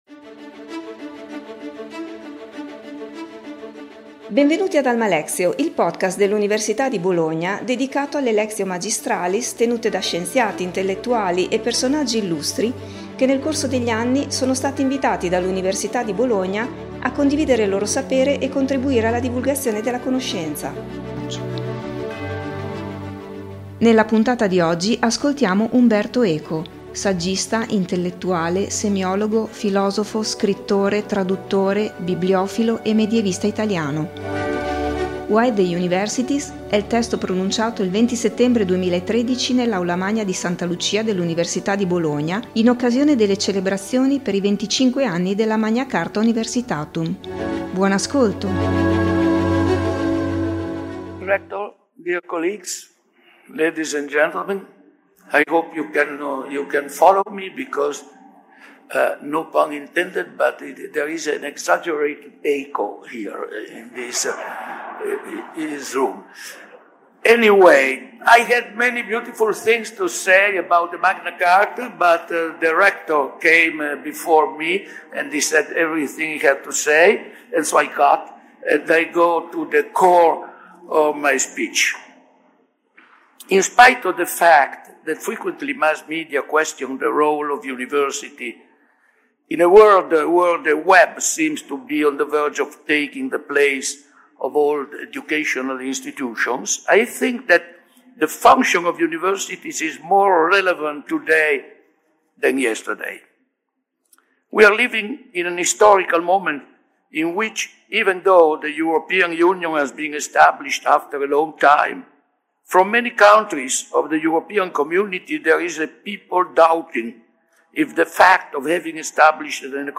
Il discorso che Umberto Eco (saggista, intellettuale, semiologo, filosofo, scrittore, traduttore, bibliofilo e medievista italiano) ha pronunciato il 20 settembre 2013 nell’Aula Magna di Santa Lucia dell’Università di Bologna in occasione delle celebrazioni per i venticinque anni della Magna Charta Universitatum.